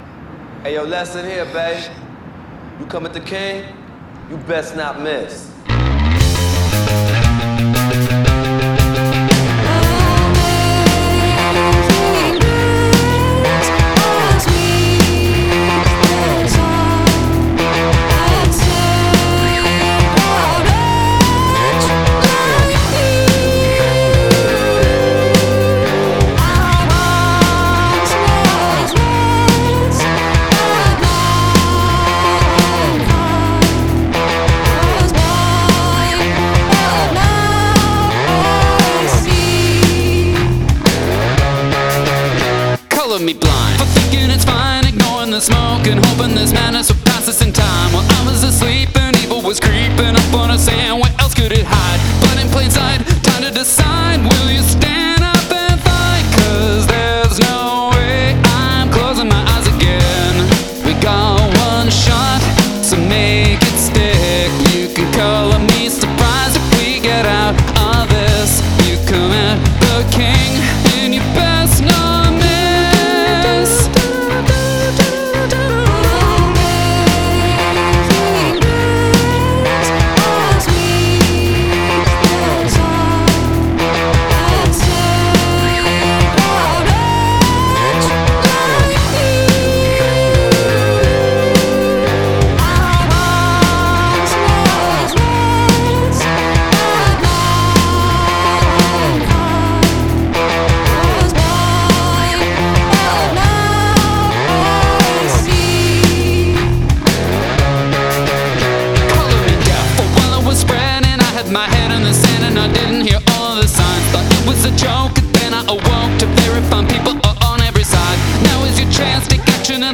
Sampled Vocal Hook